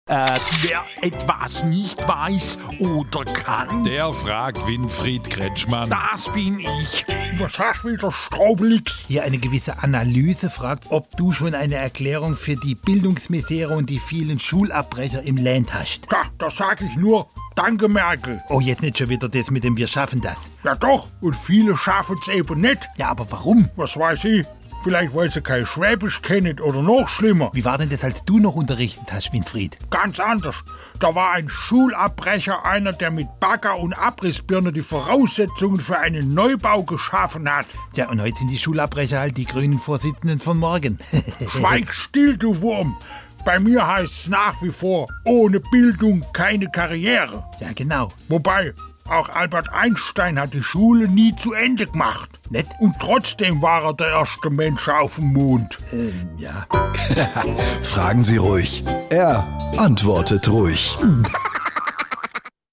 SWR3 Comedy Fragen Sie Kretschmann: Schulabbrecher